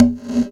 Bongo Early Ref.wav